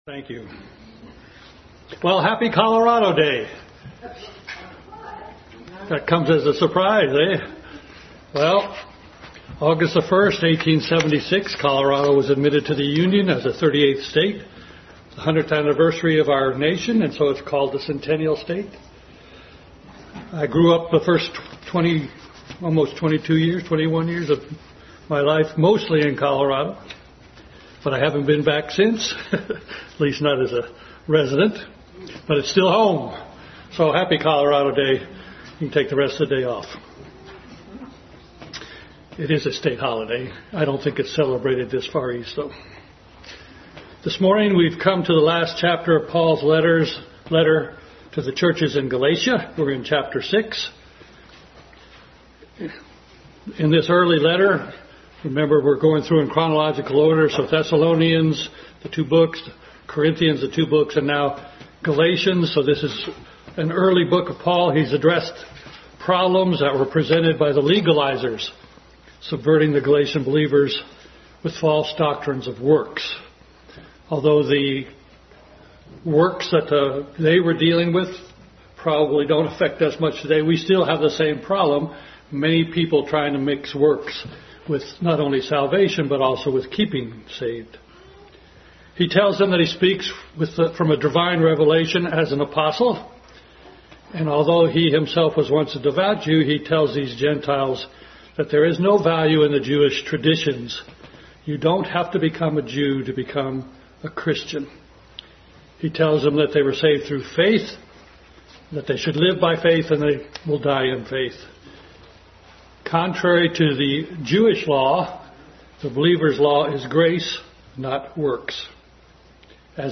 Adult Sunday School Class. Conclusion of study in Galatians.